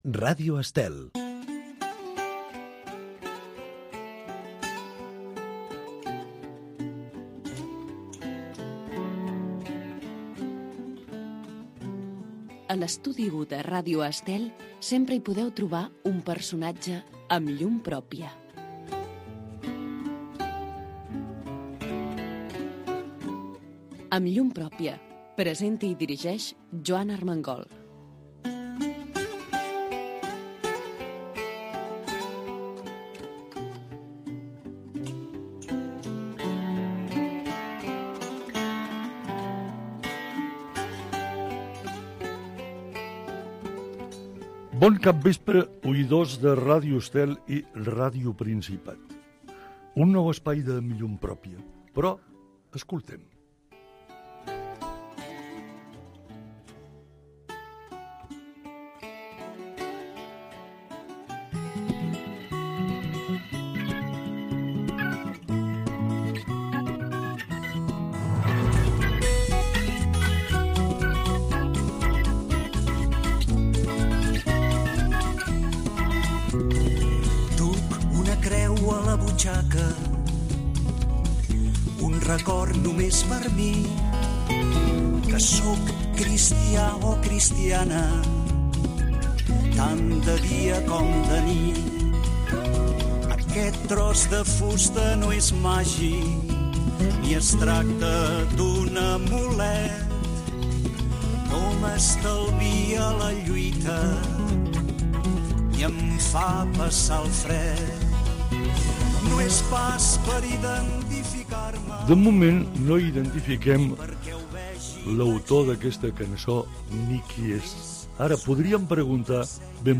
Programa d'entrevistes en profunditat a personalitats del món de la política, la societat, la cultura i els esports, que es va emetre a la sintonia de Ràdio Estel des de 1996 fins el 2012.